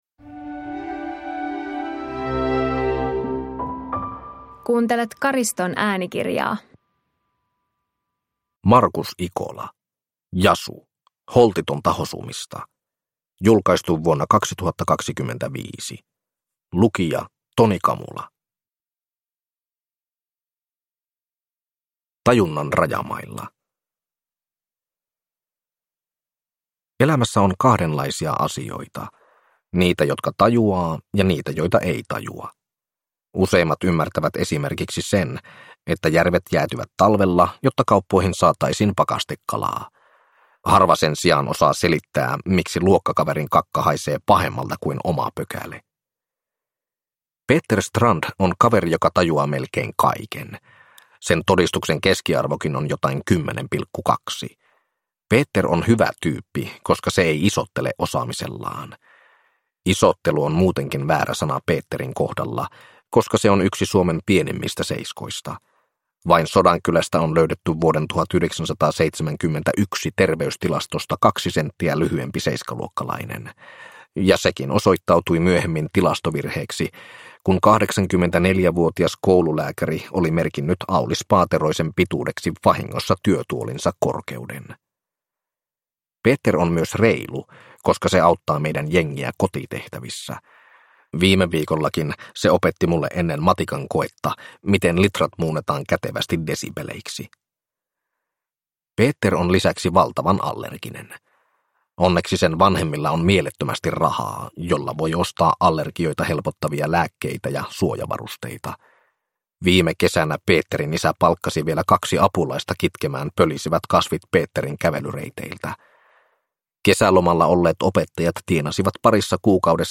Jasu – Holtitonta hosumista – Ljudbok